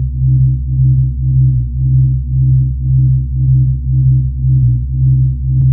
ループしてなんぼな手製背景音楽素材で〜す　５種あります
BGS仕様なんで単体で聞くとちょっとさみしい感じかもしれません。
オカルト方面にジョブチェンジしました（容量は大きめです＞＜）